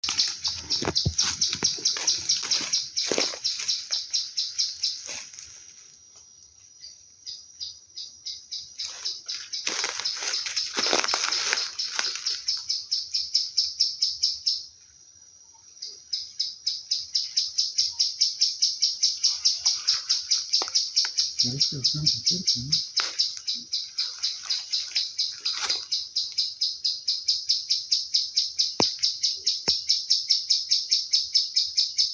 Plain-winged Woodcreeper (Dendrocincla turdina)
Location or protected area: Reserva Privada y Ecolodge Surucuá
Condition: Wild
Certainty: Photographed, Recorded vocal